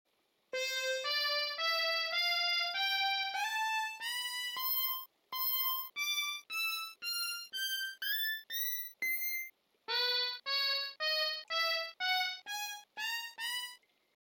Basic_Pibgorn_Sound.mp3